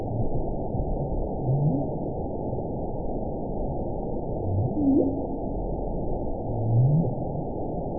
event 919616 date 01/14/24 time 05:36:06 GMT (1 year, 10 months ago) score 9.26 location TSS-AB08 detected by nrw target species NRW annotations +NRW Spectrogram: Frequency (kHz) vs. Time (s) audio not available .wav